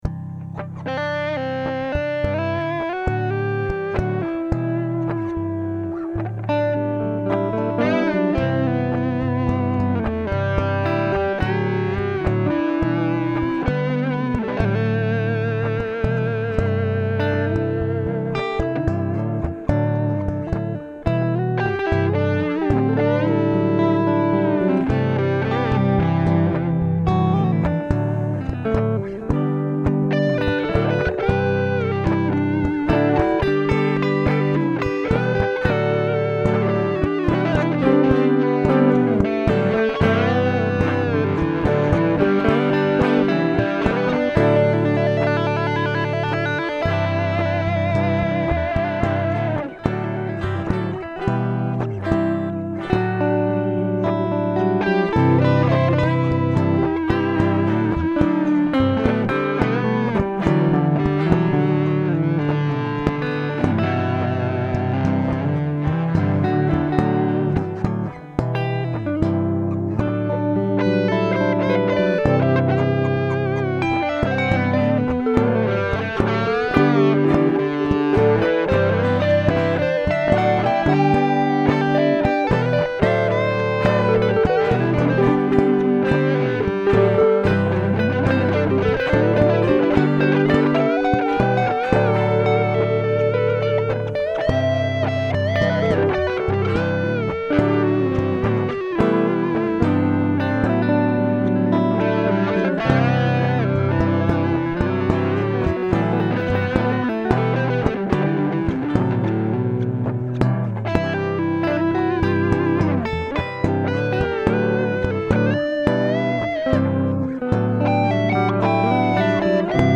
The guitar has an amazing sound, with great clarity in the lower + upper registers, with excellent sustain and nice, low action.
The effects are stock Logic presets, and the clean sounds are straight signal from the ALD 600 pre with no EQ or compression.